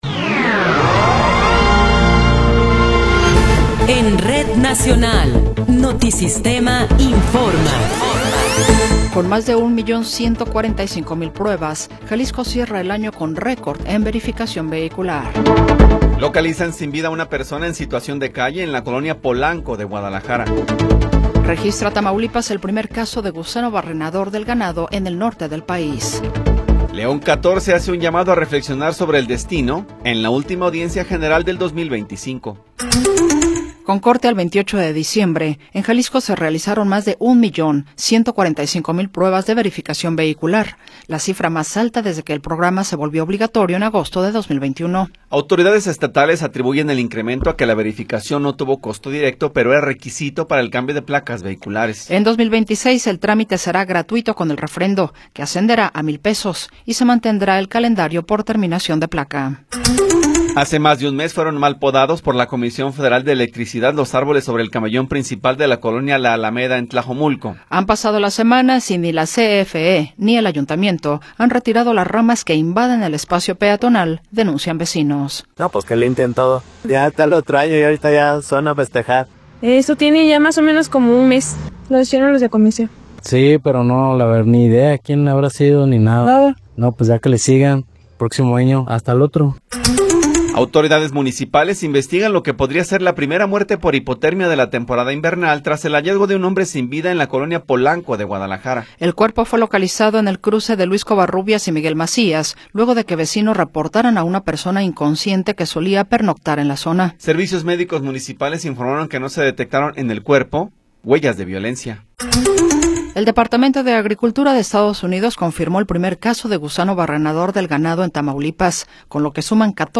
Noticiero 14 hrs. – 31 de Diciembre de 2025
Resumen informativo Notisistema, la mejor y más completa información cada hora en la hora.